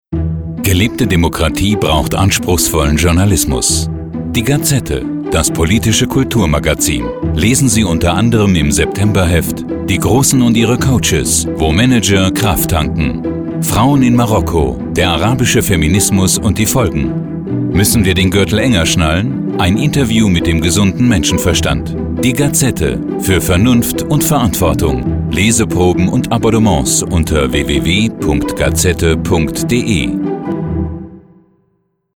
Deutscher Sprecher.
Sprechprobe: Werbung (Muttersprache):
german voice over artist